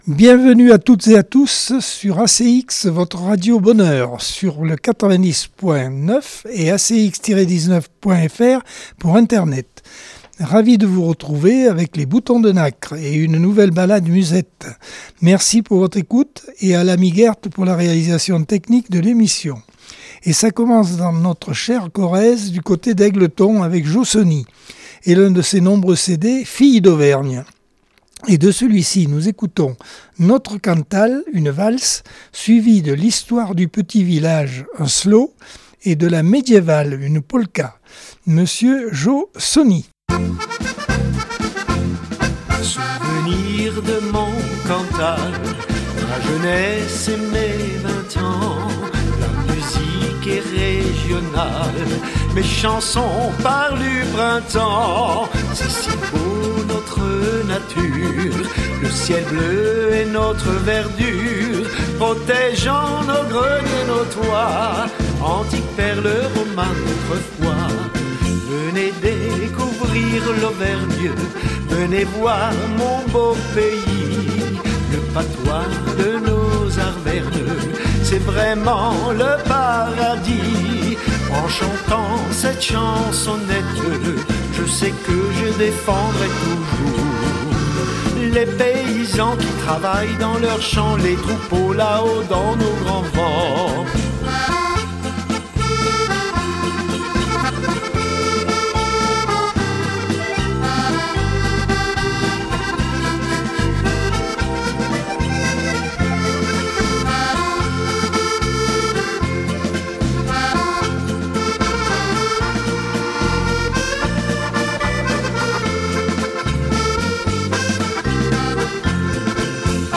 Accordeon 2024 sem 49 bloc 1 par Accordeon 2024 sem 49 bloc 1.